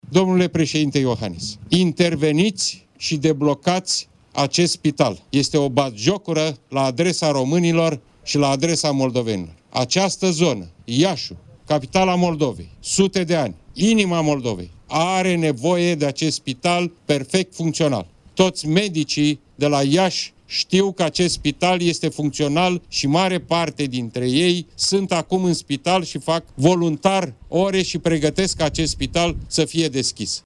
Preşedintele PSD, Marcel Ciolacu, a făcut, astăzi, apel la preşedintele Klaus Iohannis pentru a ajuta ca Spitalul Mobil de la Leţcani, care este secţie exterioară a Spitalului de Boli Infecţioase din Iaşi în perioada pandemiei de COVID-19, să devină funcţional: